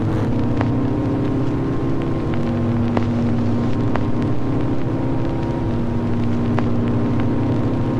实验样本01
描述：诡异的声音...
Tag: 120 bpm Weird Loops Fx Loops 1.35 MB wav Key : Unknown